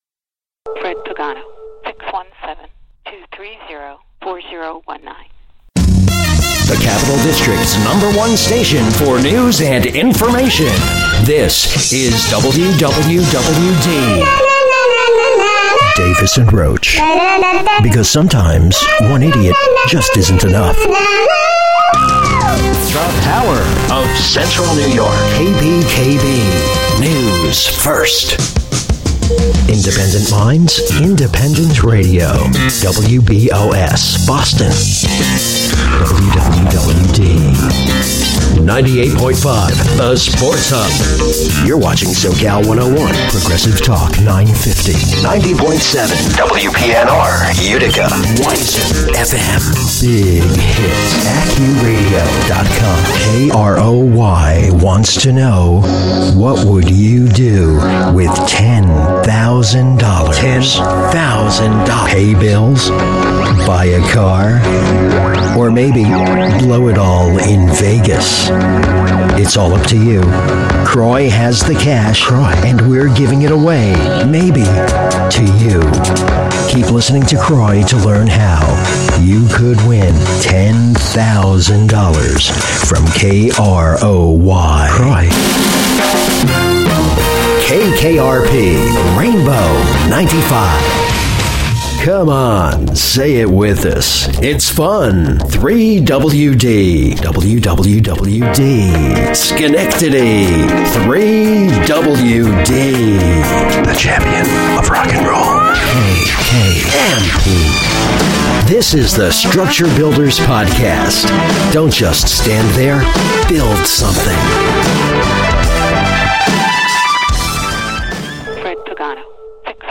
Radio & TV Imaging
From hard-hitting to light and breezy ...
imaging.mp3